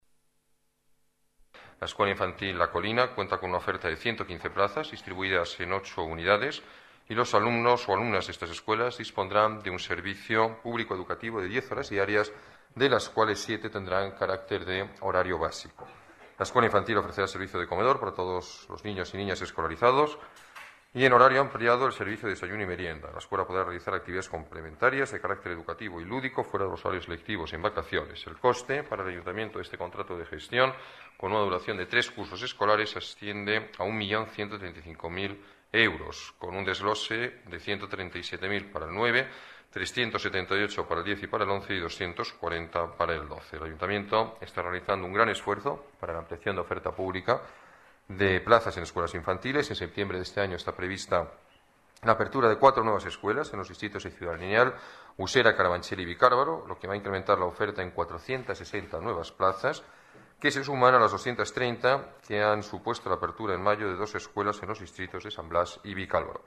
Nueva ventana:Declaraciones alcalde, Alberto Ruiz-Gallardón: Escuela Infantil La Colina